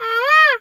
bird_peacock_squawk_08.wav